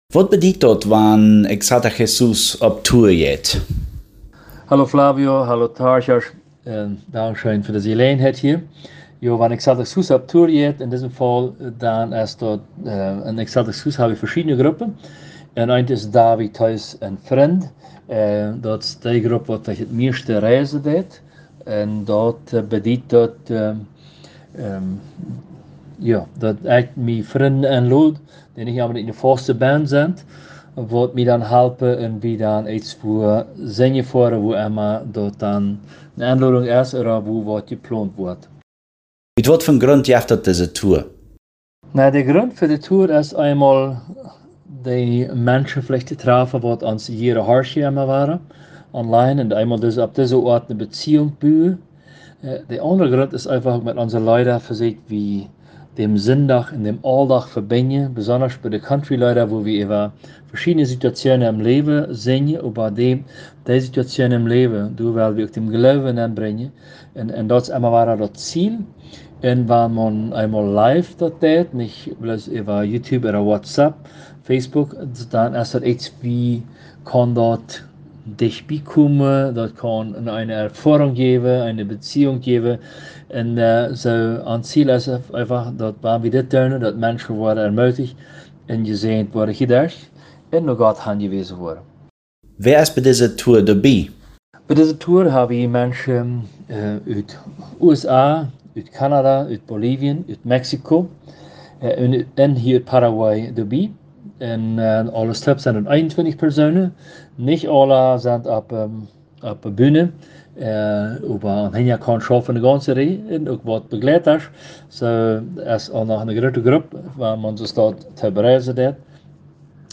Konzert